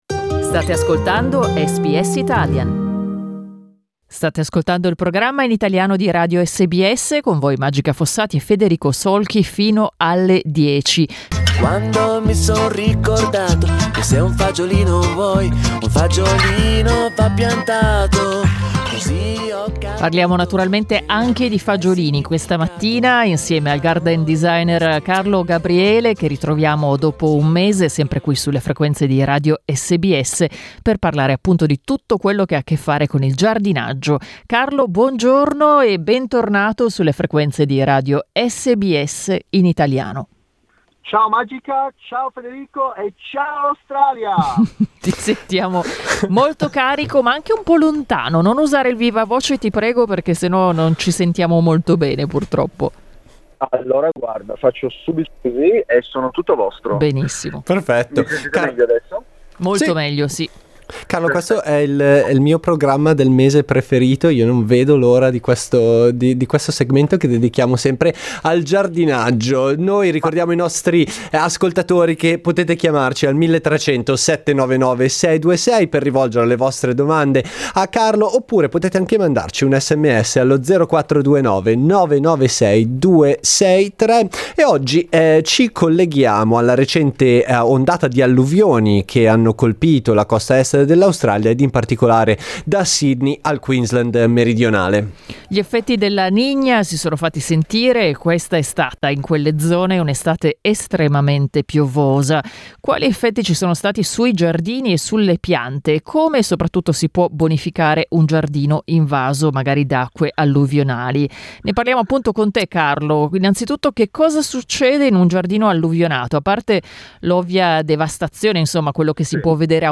Ritorna oggi il nostro appuntamento mensile con il giardinaggio